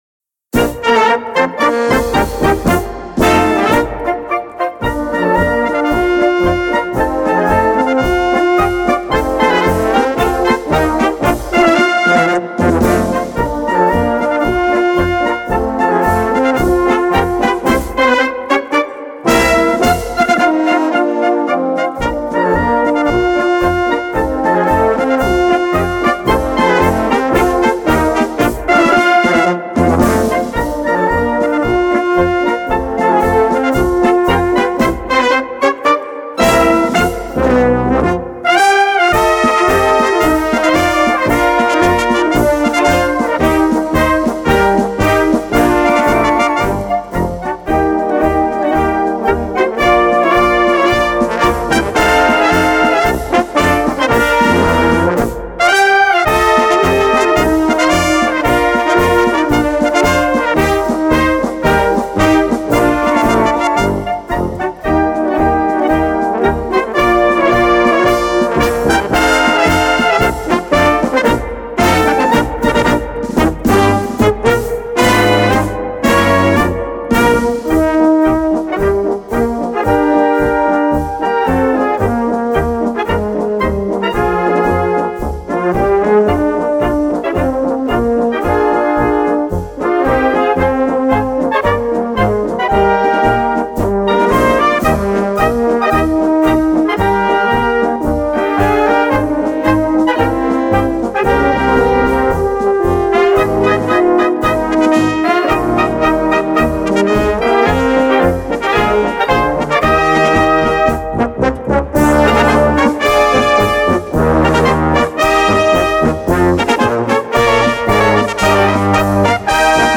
Kategorie Blasorchester/HaFaBra
Unterkategorie Strassenmarsch
Besetzung Ha (Blasorchester)